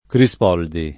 [ kri S p 0 ldi ]